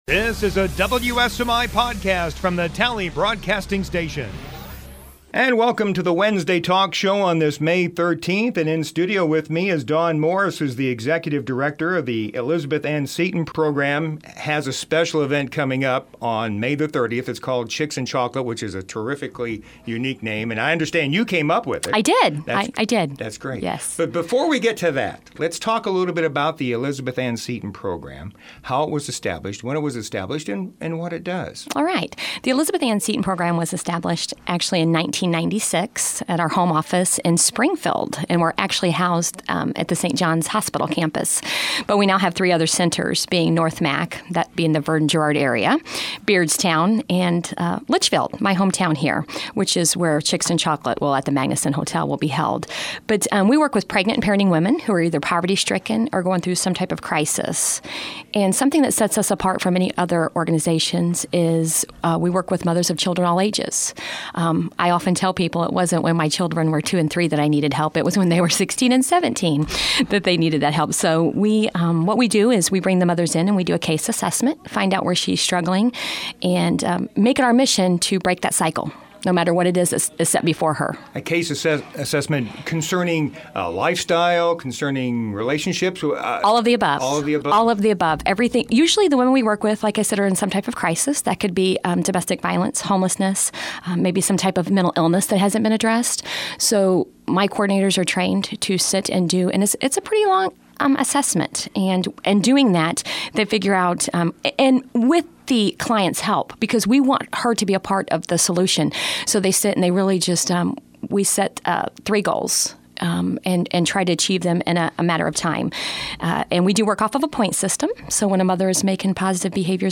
Listen: Push Play or Right-Click here to Download MP3 WebReady TM Powered by WireReady � NSI Wednesday Morning Talk Show Archives 2010 | 2011 | 2012 | 2013 | 2014 | 2015 | 2016 | 2017 | 2018 |